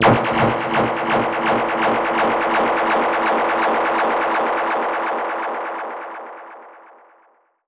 Index of /musicradar/dub-percussion-samples/125bpm
DPFX_PercHit_D_125-03.wav